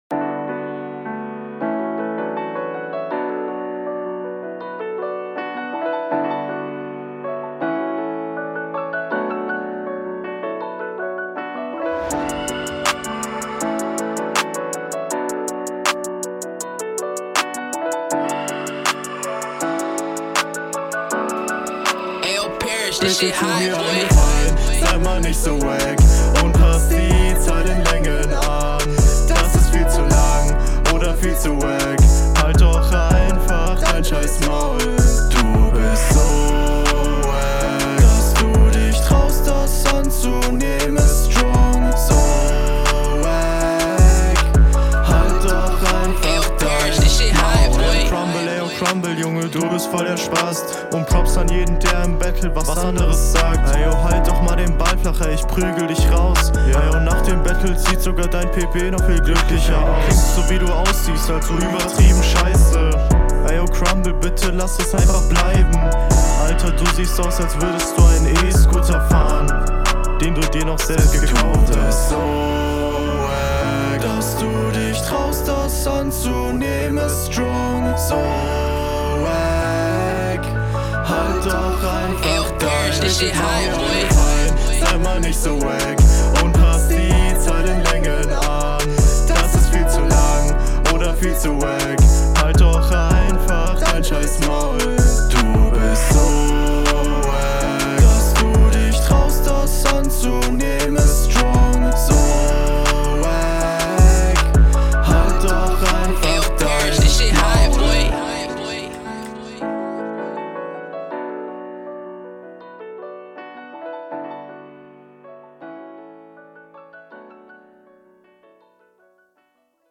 Battle Rap Bunker